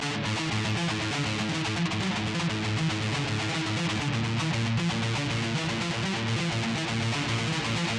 标签： 120 bpm Heavy Metal Loops Guitar Electric Loops 1.35 MB wav Key : Unknown
声道立体声